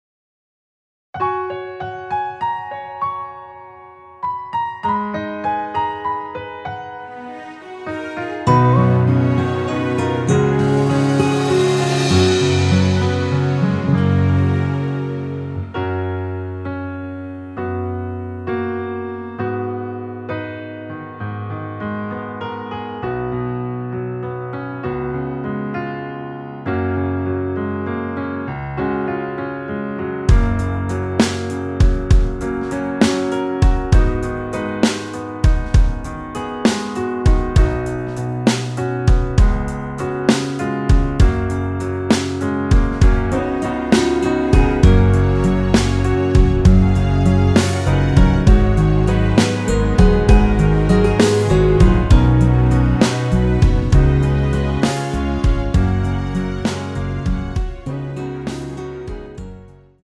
F#
◈ 곡명 옆 (-1)은 반음 내림, (+1)은 반음 올림 입니다.
앞부분30초, 뒷부분30초씩 편집해서 올려 드리고 있습니다.
중간에 음이 끈어지고 다시 나오는 이유는